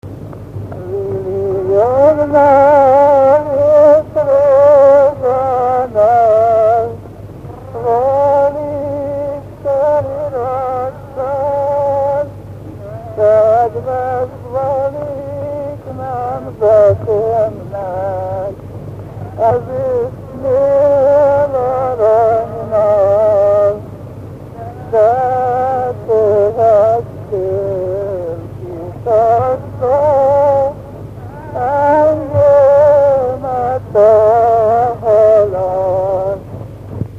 Dunántúl - Sopron vm. - Vitnyéd
Régies kisambitusú dallamok